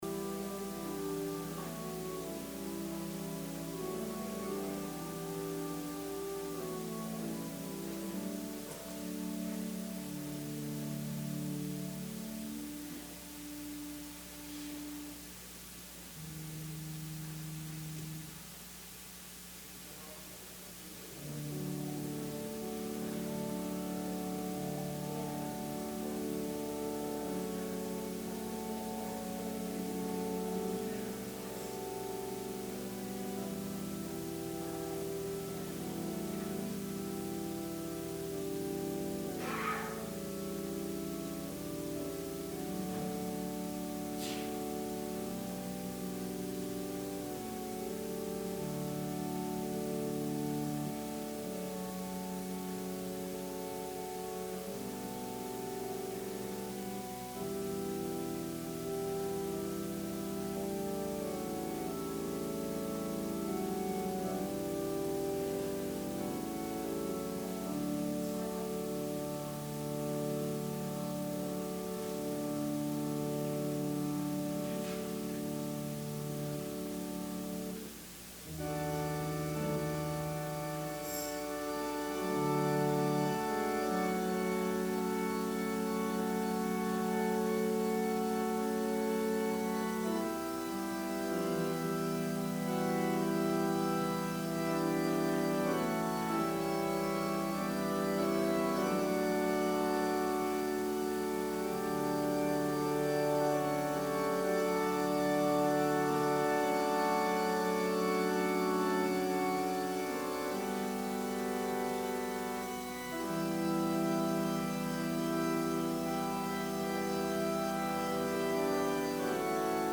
Sermon – October 4, 2020
advent-sermon-oct-4-2020.mp3